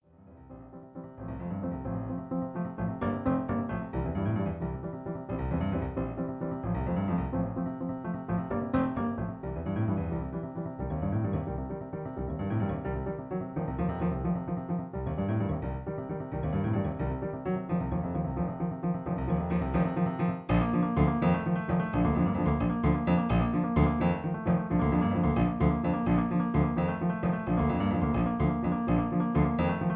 In B flat. Piano accompaniment